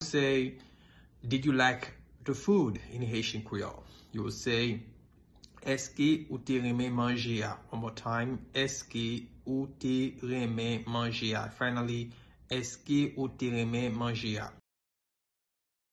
Listen to and watch “Èske ou te renmen manje a?” pronunciation in Haitian Creole by a native Haitian  in the video below:
Did-you-like-the-food-in-Haitian-Creole-Eske-ou-te-renmen-manje-a-pronunciation.mp3